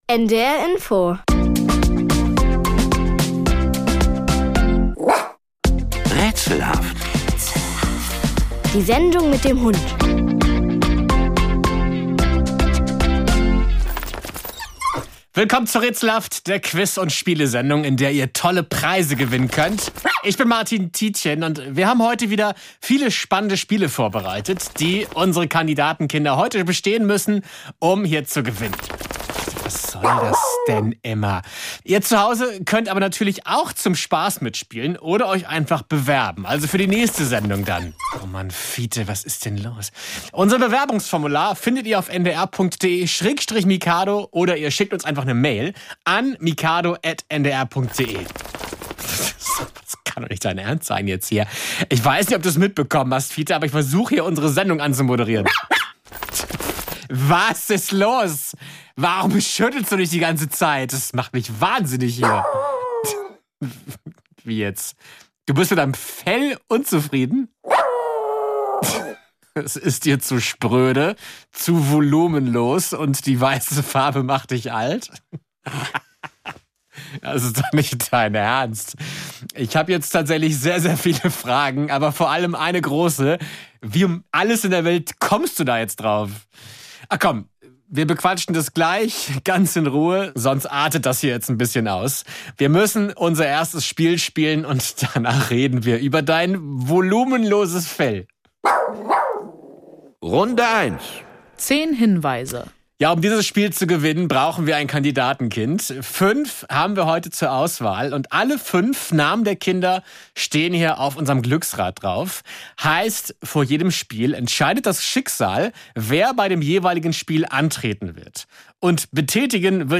Das Quiz zum Mitmachen und Mitlachen.